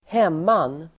Ladda ner uttalet
Folkets service: hemman hemman substantiv (äldre term), homestead [archaic] Uttal: [²h'em:an] Böjningar: hemmanet, hemman, hemmanen Synonymer: gård Definition: jordbruksfastighet (agricultural property)